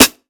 Sampled Snare.wav